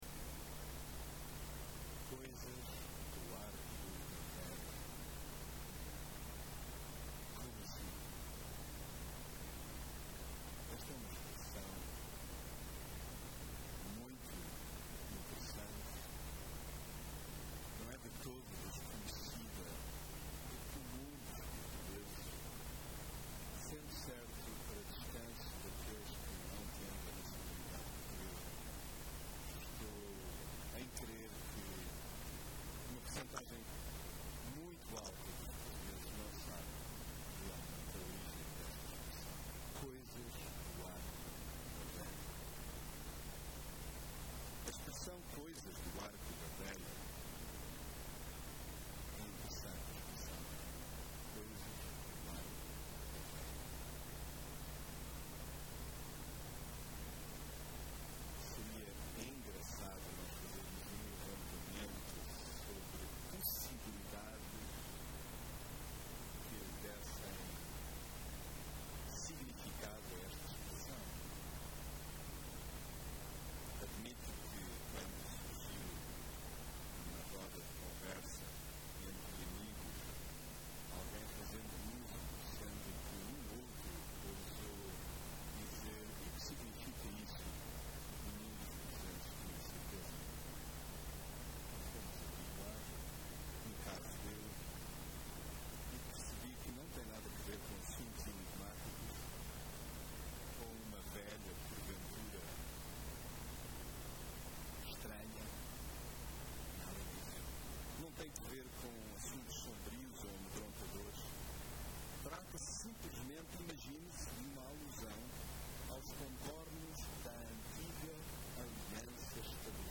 mensagem bíblica Presumimos estar tão prontos para seguir a Deus que grande parte das vezes nem O ouvimos, pelo menos como deve ser.